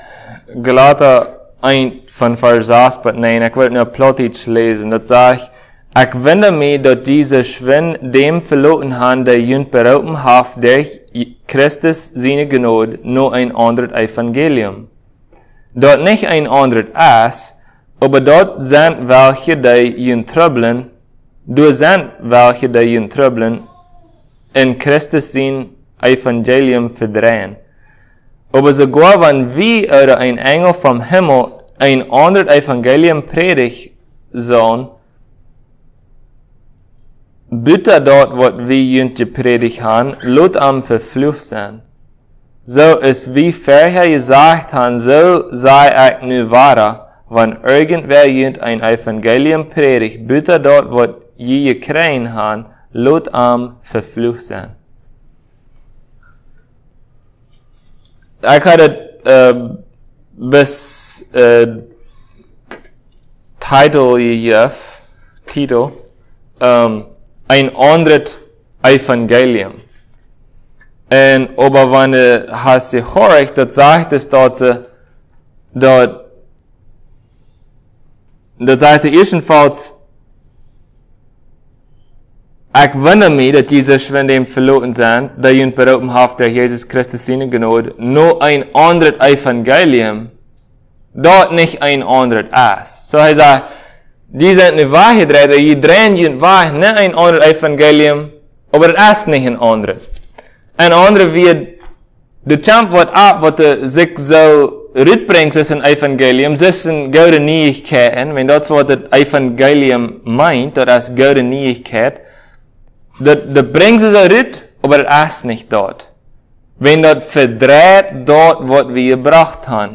Passage: Galatians 1:6-9 Service Type: Sunday Plautdietsch